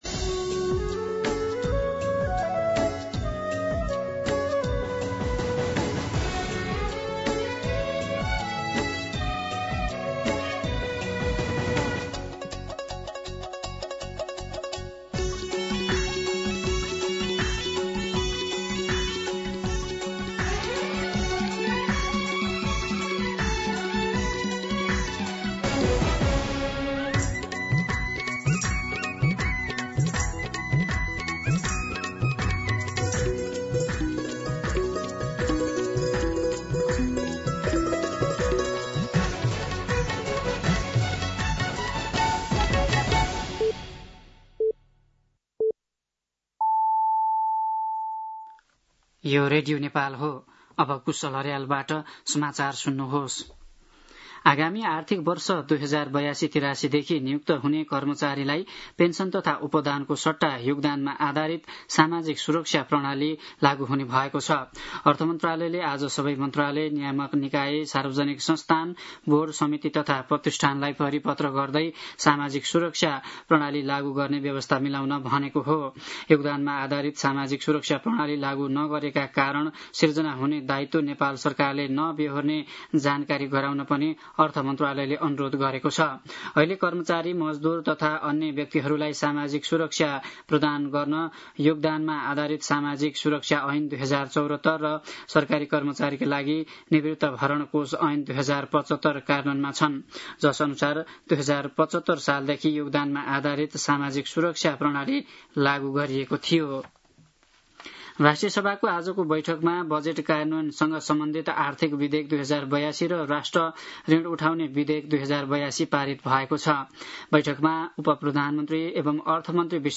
दिउँसो ४ बजेको नेपाली समाचार : २० असार , २०८२